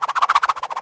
На этой странице собраны звуки бумеранга: от свиста в полете до мягкого приземления в руку.
Мультяшный звук пролетающего мимо бумеранга